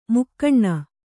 ♪ mukkaṇṇa